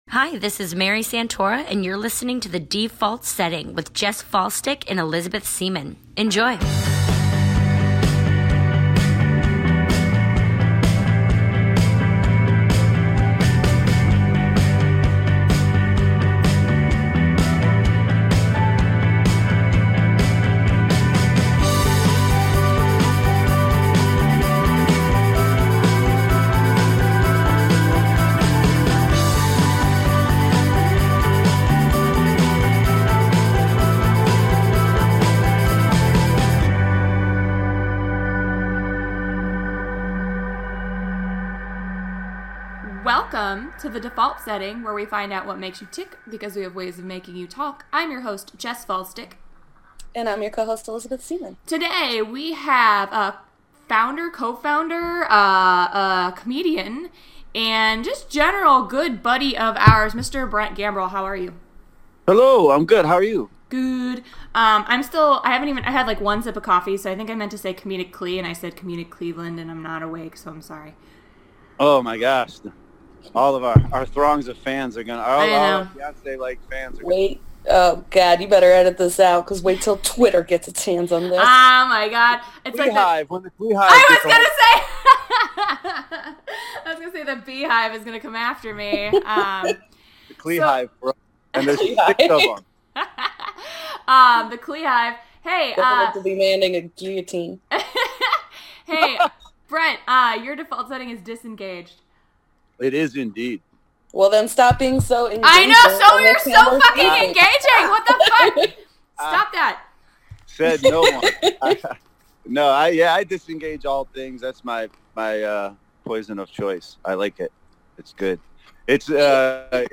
Also: we have a singalong, we dish on who's who and what's what in Cleveland comedy and we laugh so hard while getting so very dark.